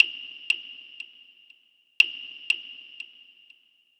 Lab Work (Tap) 120BPM.wav